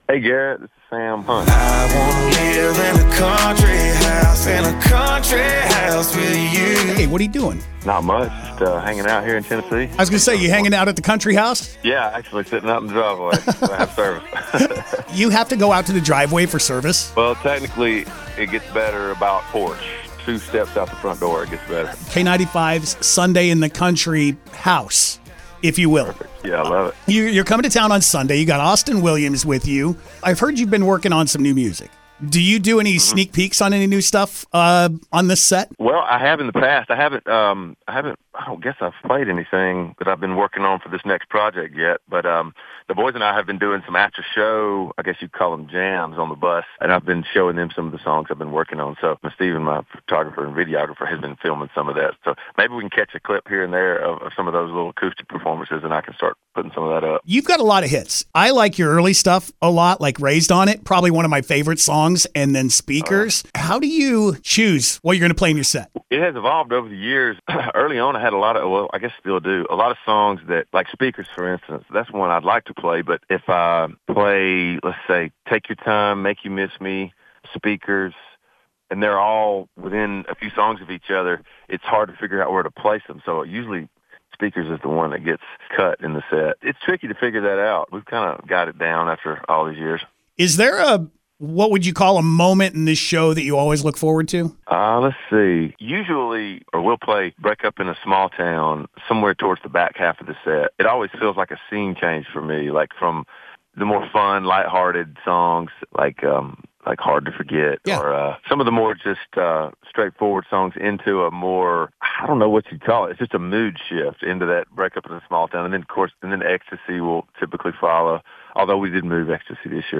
kh-sam-hunt-intv-final.mp3